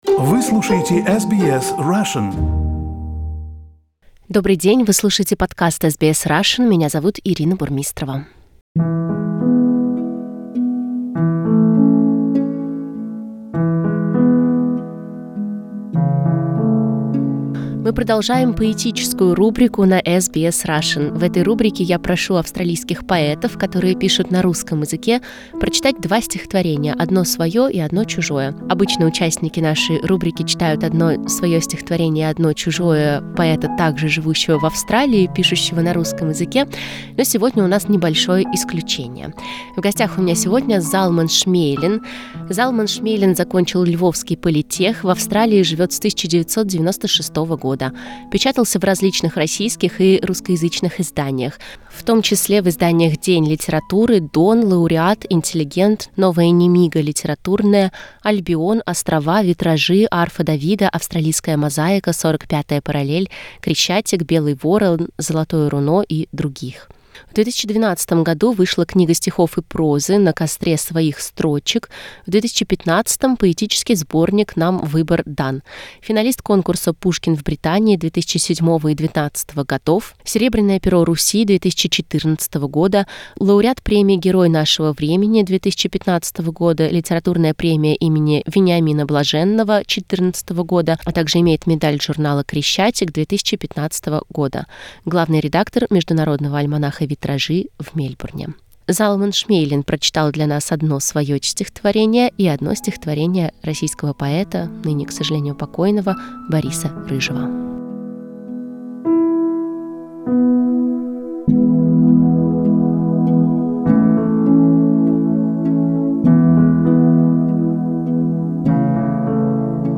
В этой рубрике мы просим австралийских поэтов прочесть два стихотворения: одно свое и одно чужое.